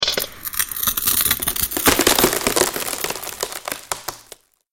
Звуки трескающегося стекла
Звук трескающегося под давлением стекла